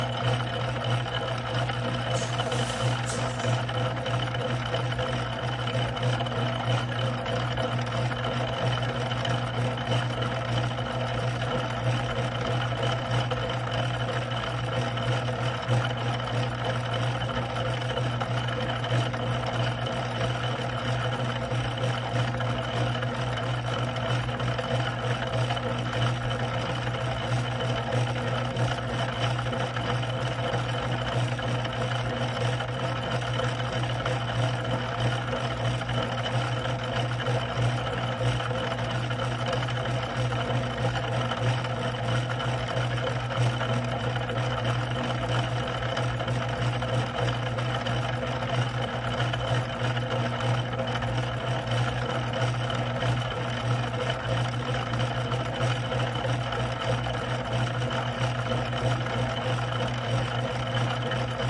金属加工厂" 机器金属切割器研磨机 滚筒关闭2
Tag: 切割机 关闭 机器 金属 粉碎机